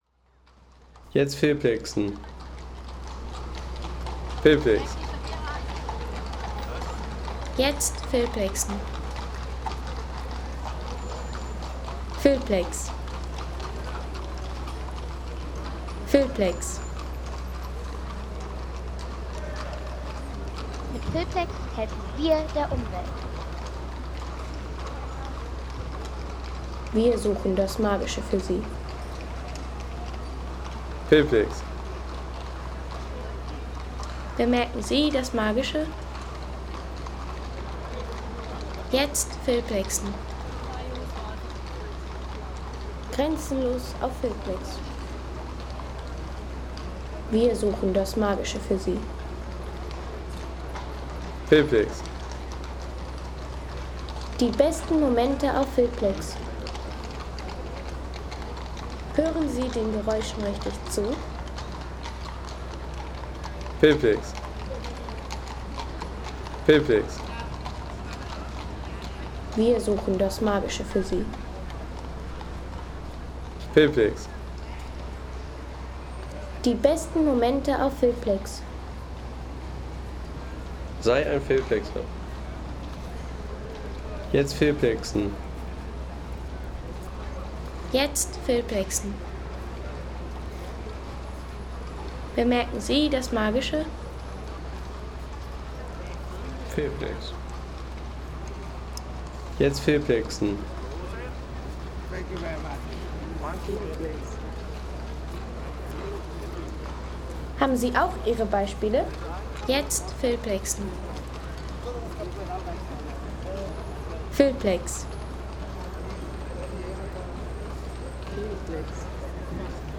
Entdecken Sie die Klänge des Wiener Michaelerplatzes: Touristen, Stadtverkehr und Pferdekutschen inmitten barocker Architektur.